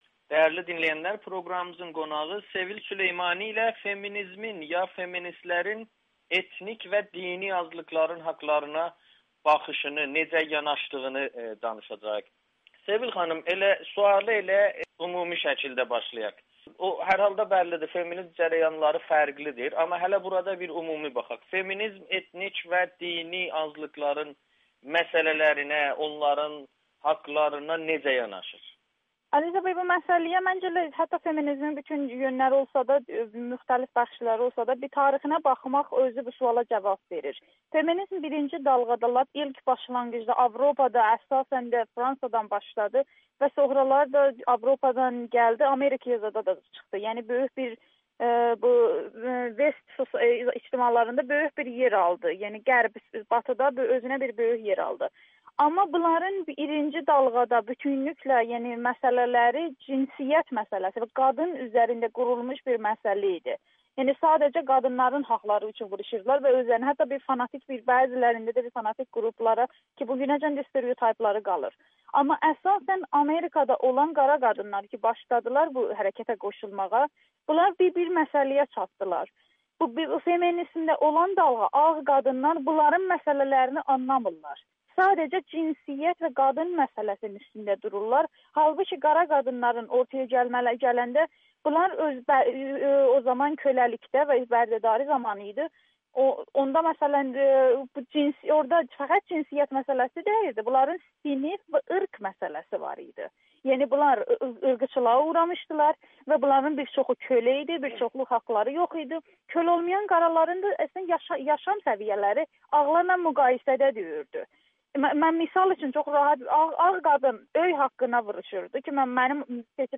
şiə və fars bir qadını təsvir edir [Audio-Müsahibə]